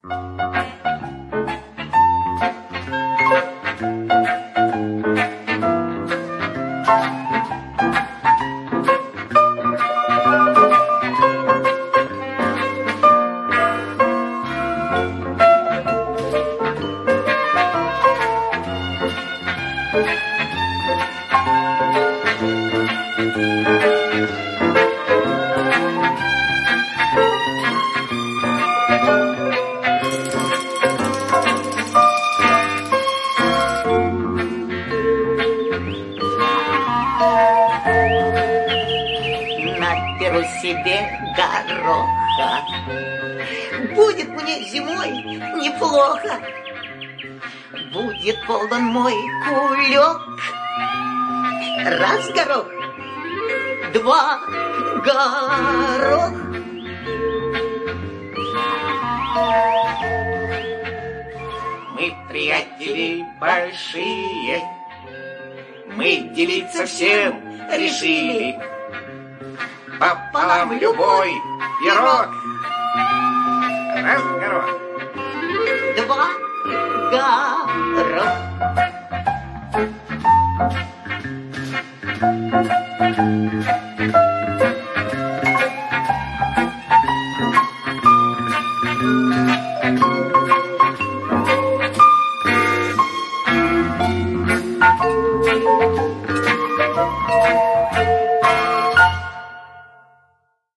Лиричная песенка, которую интересно послушать с детьми.
композиция из мультфильма